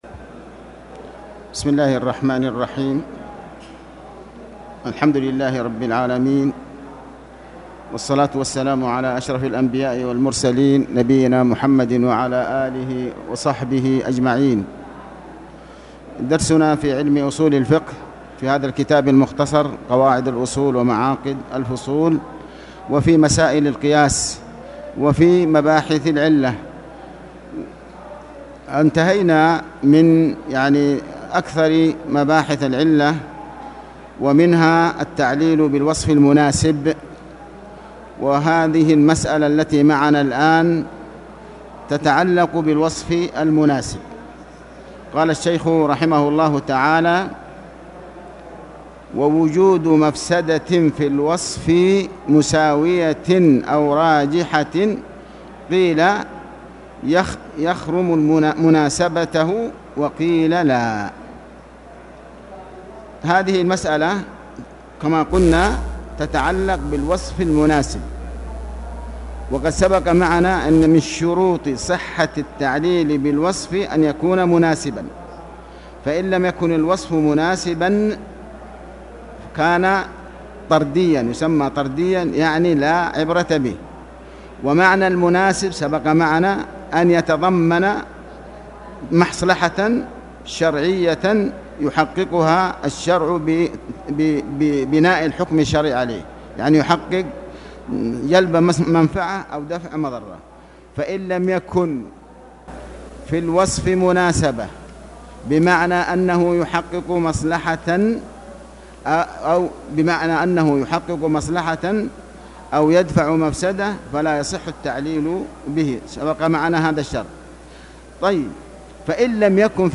تاريخ النشر ١٦ جمادى الآخرة ١٤٣٨ هـ المكان: المسجد الحرام الشيخ